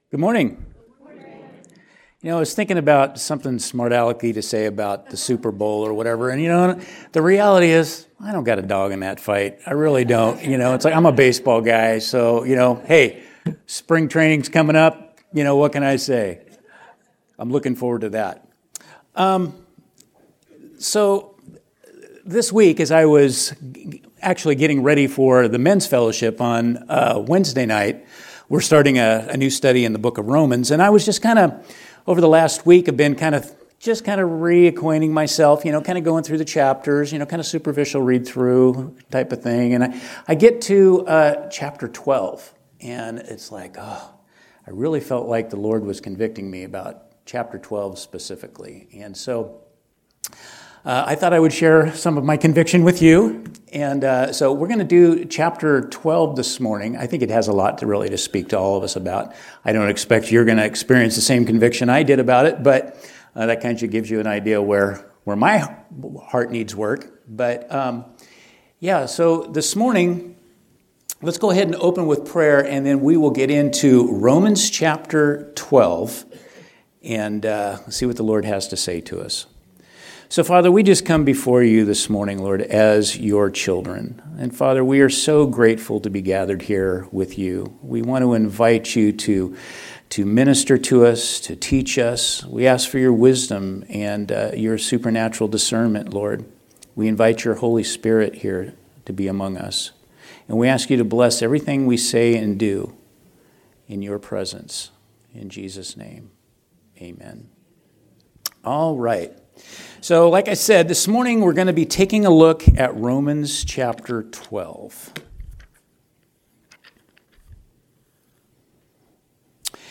Teaching from Sunday AM service